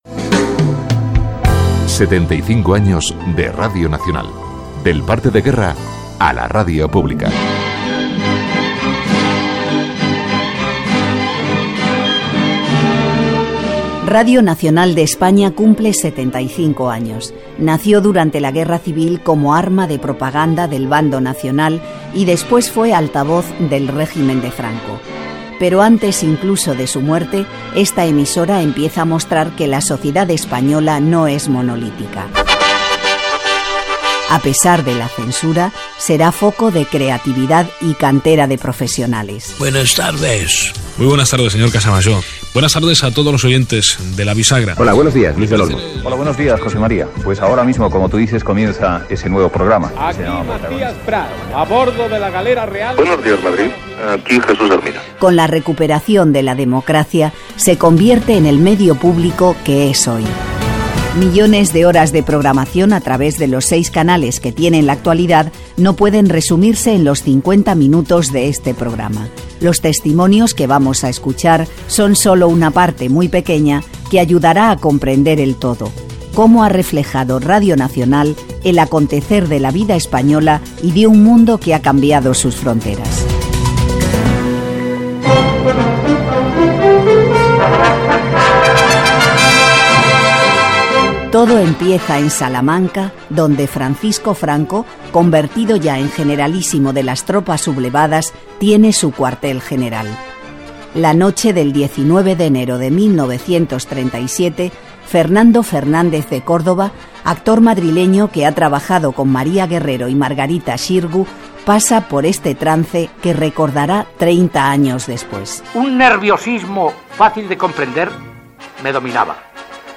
Programa especial amb motiu del 75 aniversari de RNE.
Divulgació